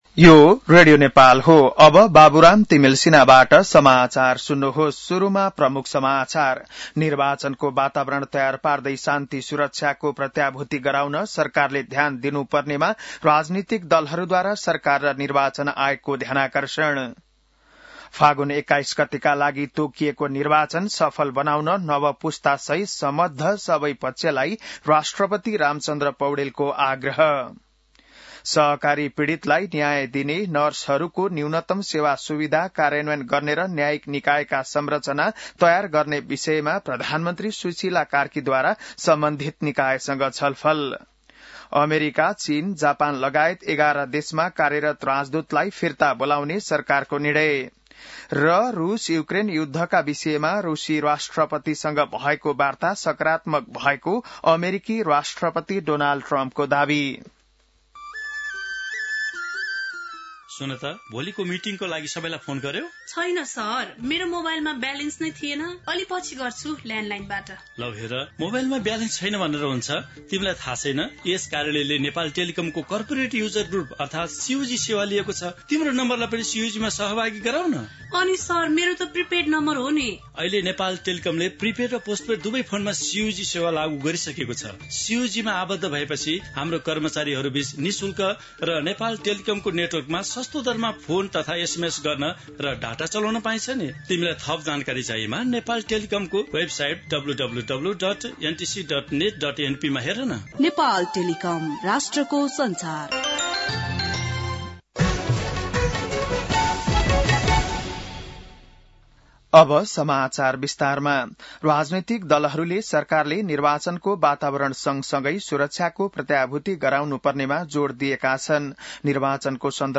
बिहान ७ बजेको नेपाली समाचार : ३१ असोज , २०८२